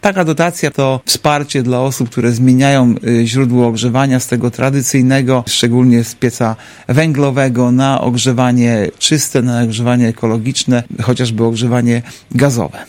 – Tym razem pieniądze trafią do 10 osób, na łączną kwotę 27 tysięcy złotych – mówi Artur Urbański, zastępca prezydenta Ełku.